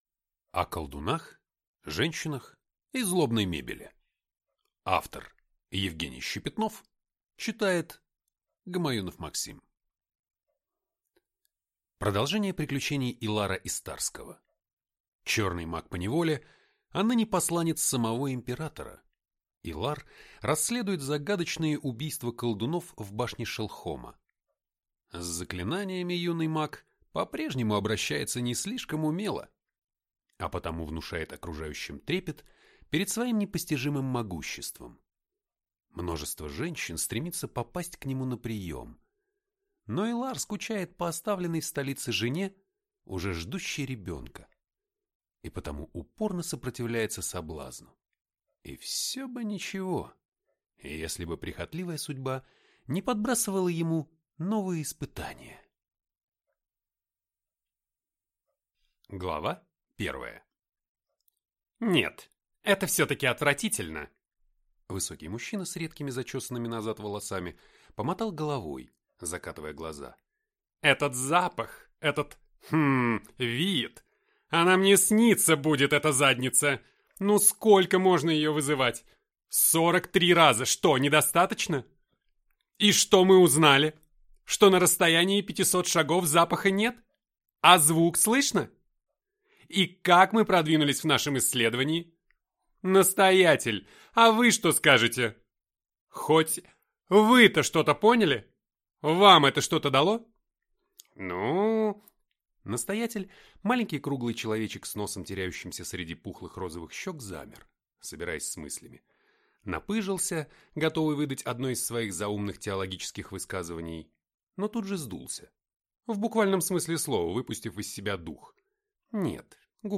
Аудиокнига О колдунах, женщинах и злобной мебели - купить, скачать и слушать онлайн | КнигоПоиск